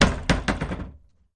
Cellar Door, Lighter, Open and Bounce